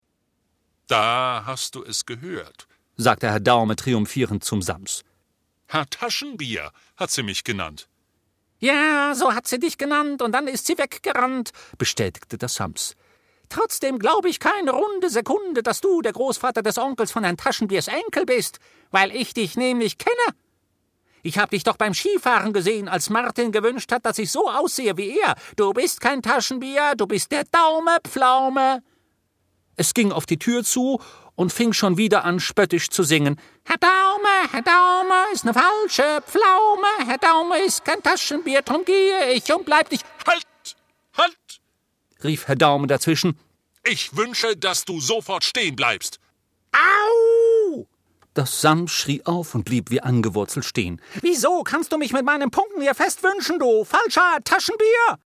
Rufus Beck (Sprecher)